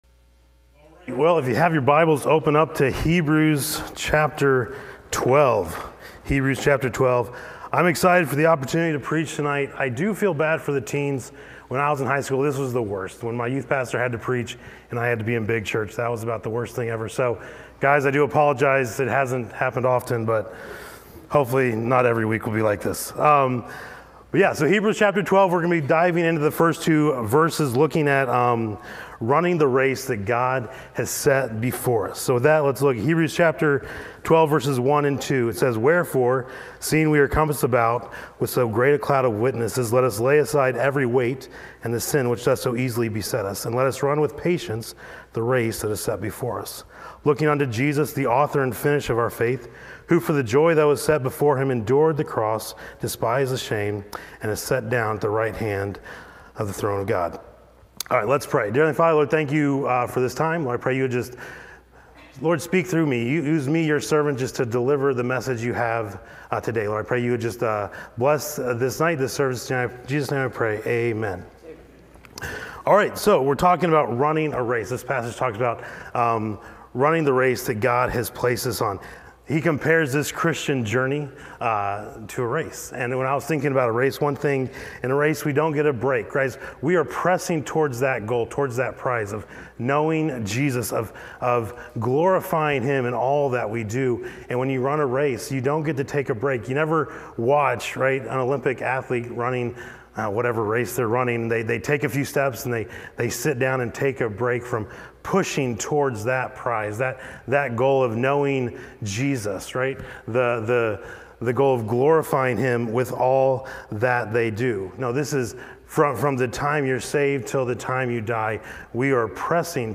Wed Night Bible Study